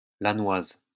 [lanwaz](info) bzw.